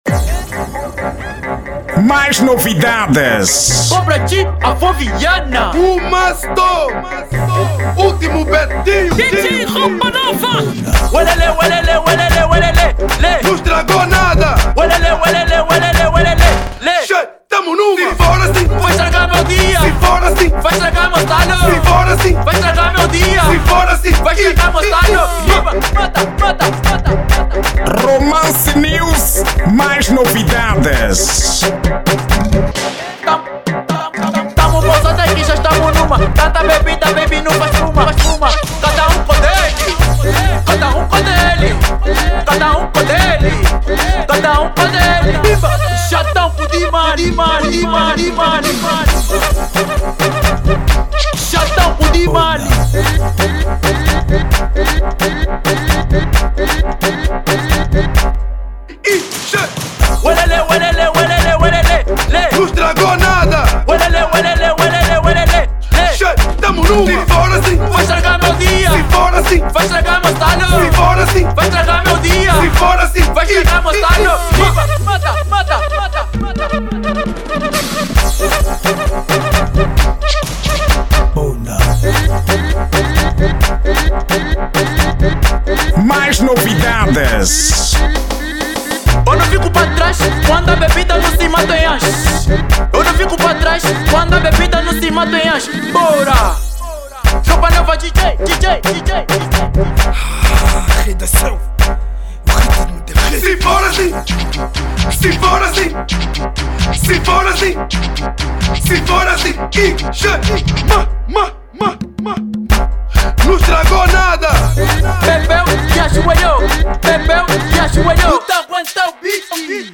Estilo: Afro House